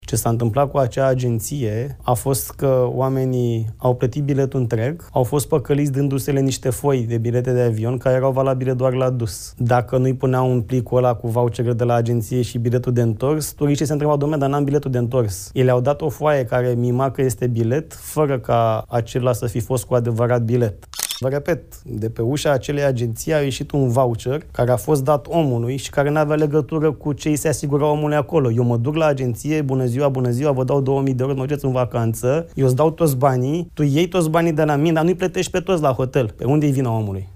Ministrul Radu Miruță, într-o dezbatere organizată de StartUp Café: „Oamenii au plătit biletul întreg, au fost păcăliți, dându-li-se niște bilete de avion care erau valabile doar la dus”